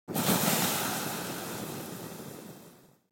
دانلود آهنگ آتشفشان 7 از افکت صوتی طبیعت و محیط
دانلود صدای آتشفشان 7 از ساعد نیوز با لینک مستقیم و کیفیت بالا
جلوه های صوتی